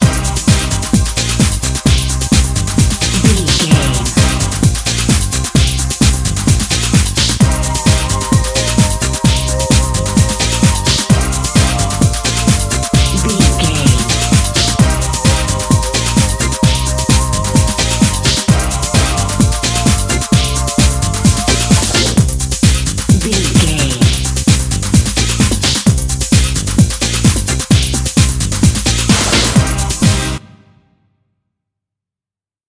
Aeolian/Minor
Fast
drum machine
synthesiser
electric piano
bass guitar
conga